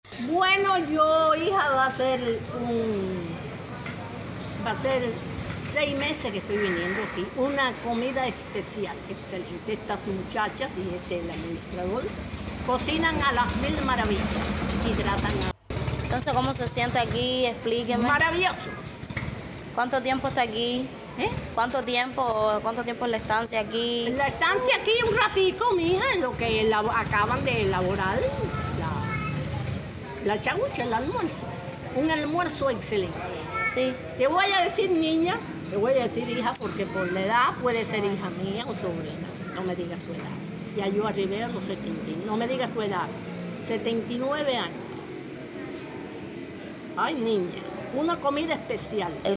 Sobre el buen trato que brindan a los comensales en el centro de gastronomía explica la anciana.